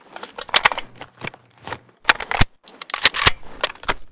ak12_reload_empty.ogg